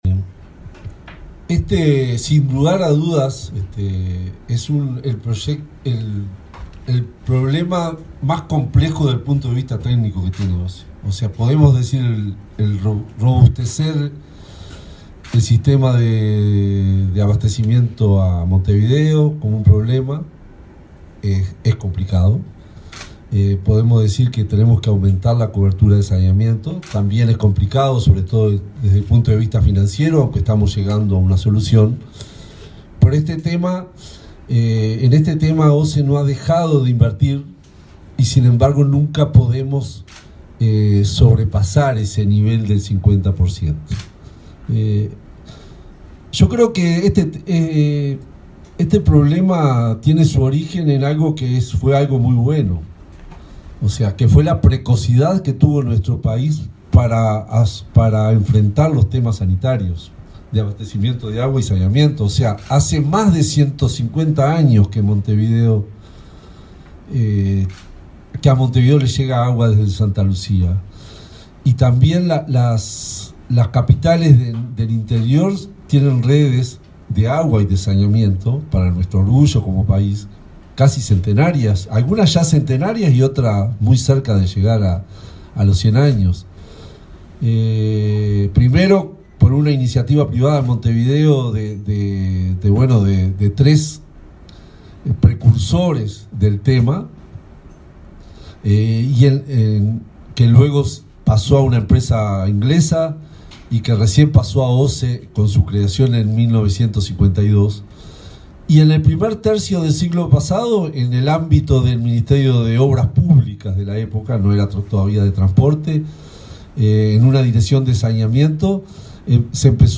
Palabra de autoridades en convenio entre OSE y el Ministerio de Ambiente